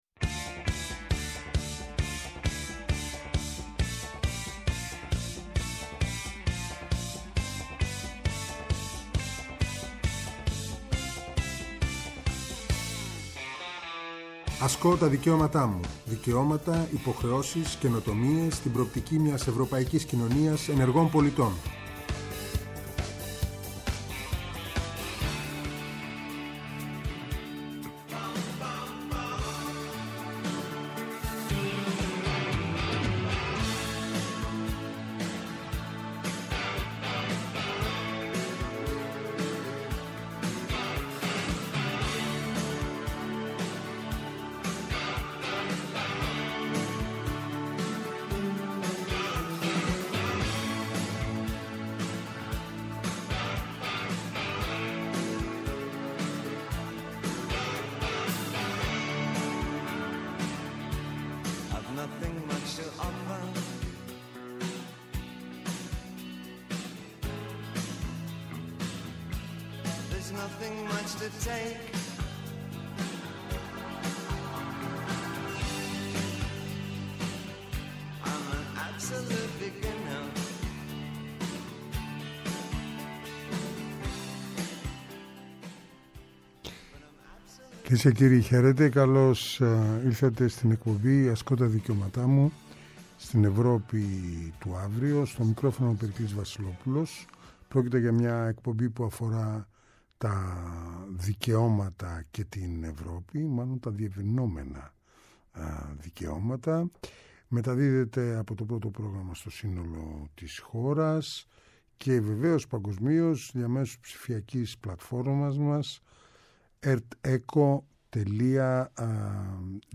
Αυτό το Σάββατο 18 Νοεμβρίου 2023, 4–5 μ.μ. καλεσμένοι :